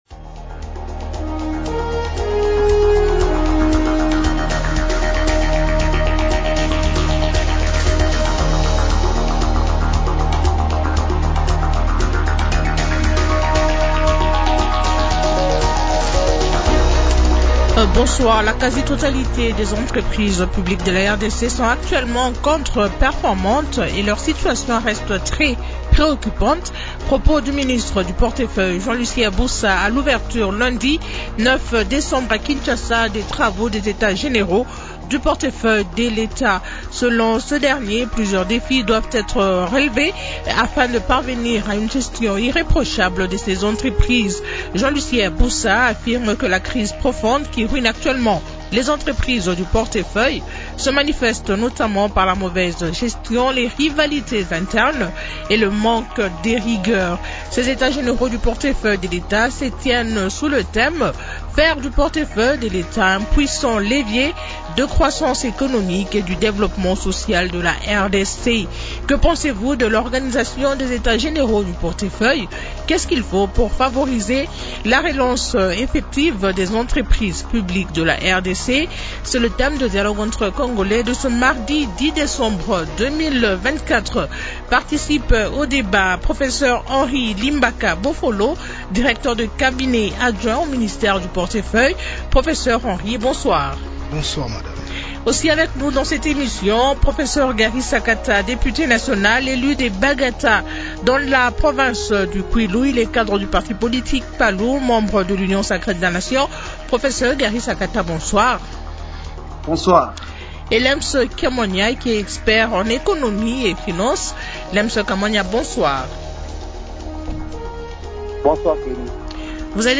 expert en économie et Finances